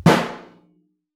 timpsnaretenor_fff.wav